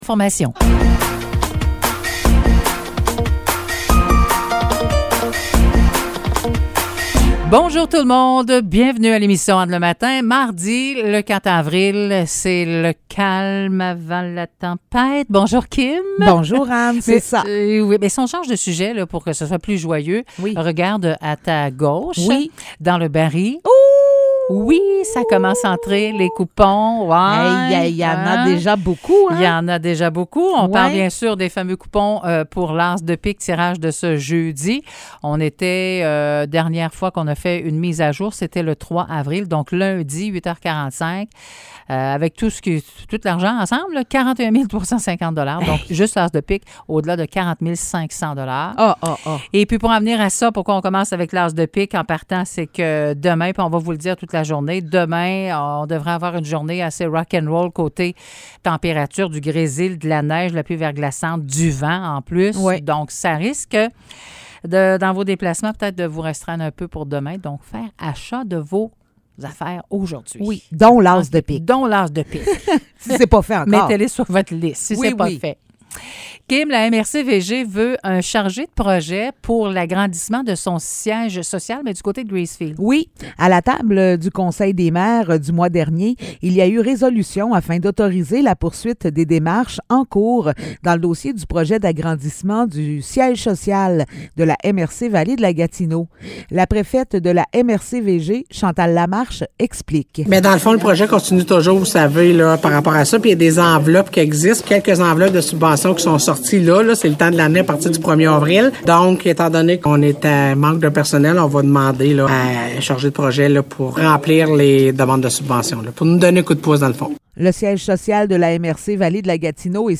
Nouvelles locales - 4 avril 2023 - 9 h